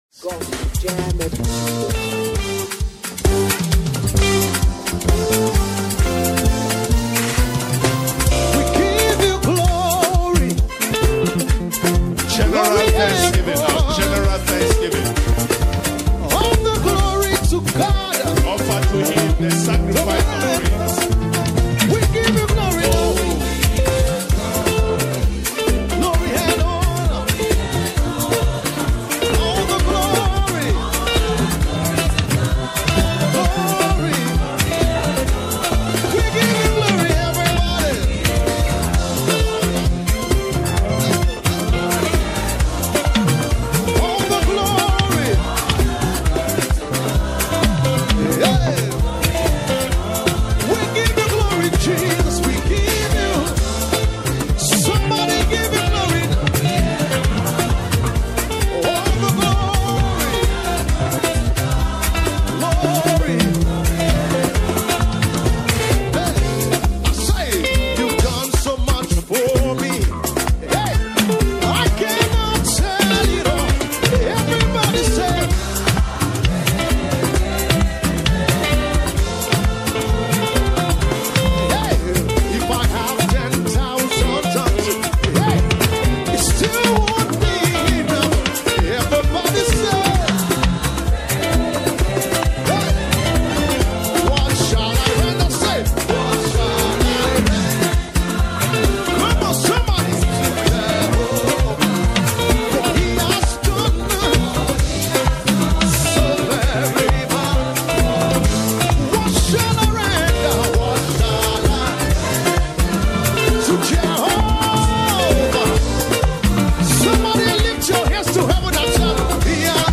Popular gospel singer
dance as you like
First Sunday of 2025.